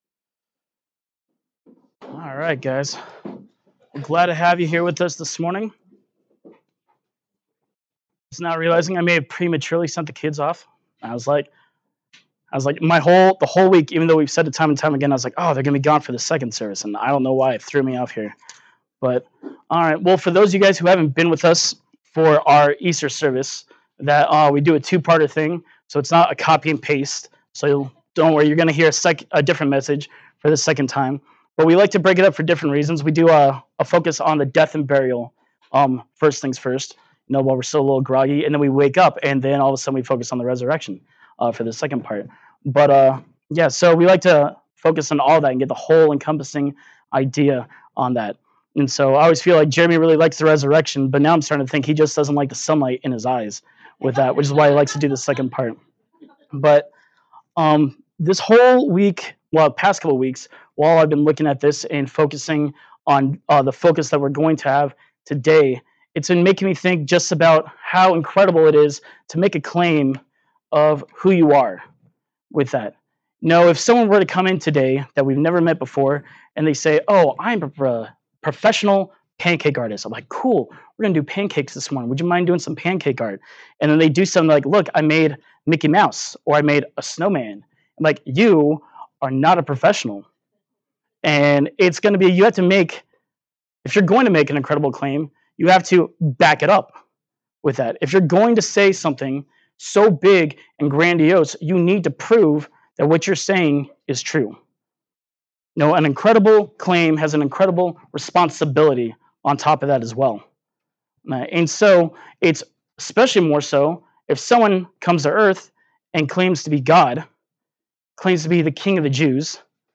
Easter Son Rise Service 2023